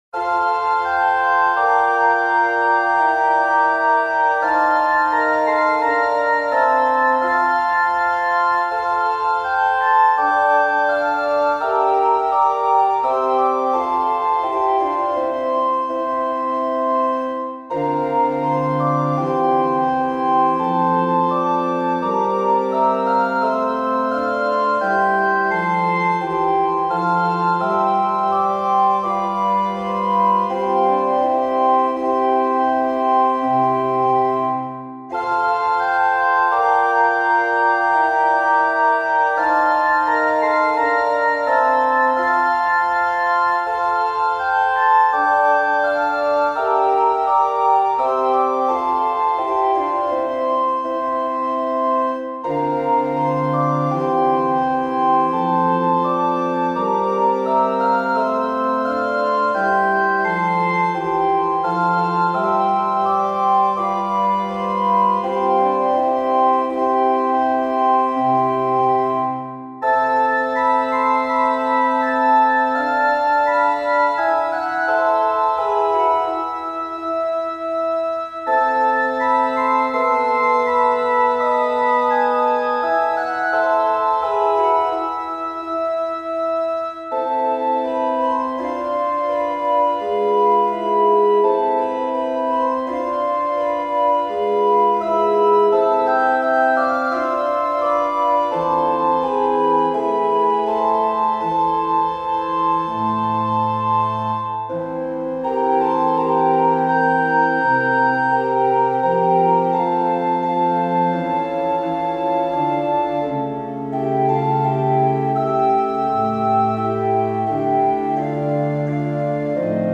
• サウンドプログラマがDTM(打ち込み)で制作した高品質なクラシックmp3を試聴・ダウンロードできます。
＜オルガン＞
♪オルガンフルートという丸みのある綺麗なパイプオルガン音色を使用。